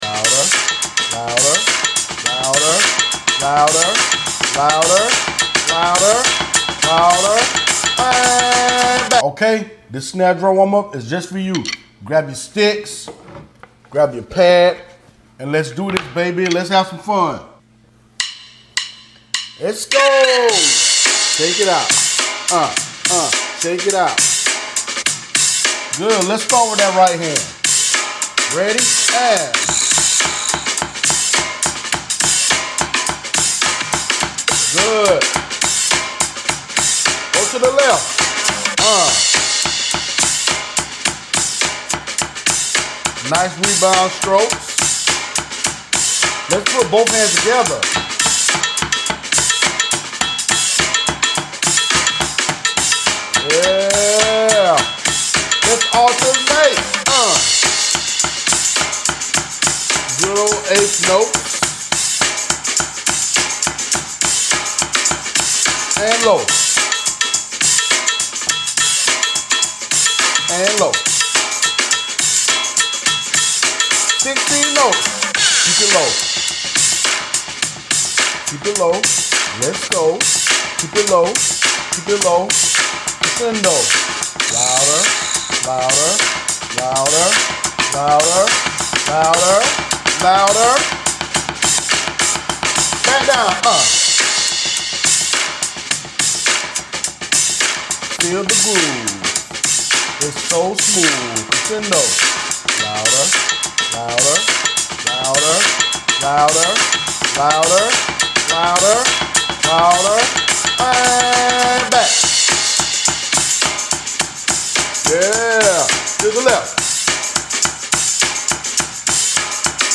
🥁🔥 Snare Drum Time! 🔥🥁 Grab your drum pad, grab your sticks, and try to keep up with this Atlanta Drum Academy snare drum play-along.